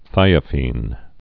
(thīə-fēn)